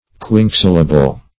Search Result for " quinquesyllable" : The Collaborative International Dictionary of English v.0.48: Quinquesyllable \Quin"que*syl`la*ble\, n. [Quinque- + syllable.] A word of five syllables.